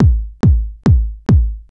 Techno / Drum / KICK020_TEKNO_140_X_SC2.wav